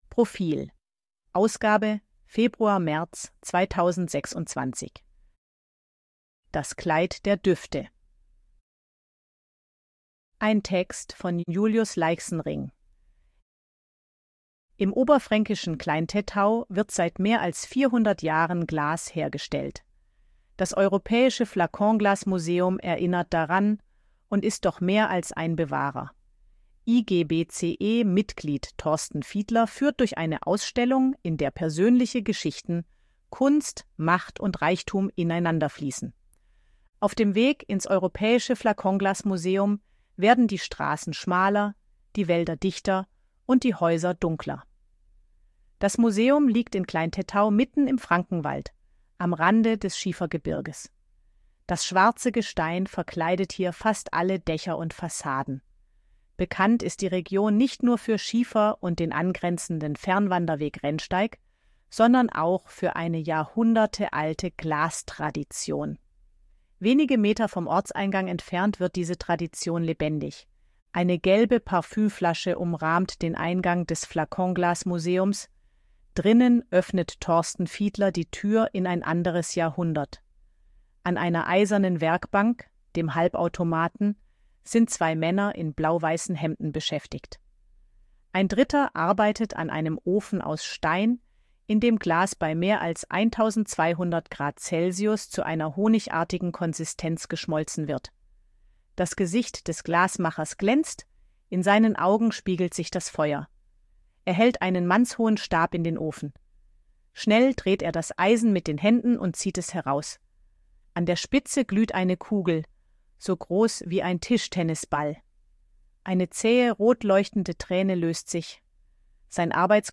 Artikel von KI vorlesen lassen
ElevenLabs_261_KI_Stimme_Frau_Betriebsausflug.ogg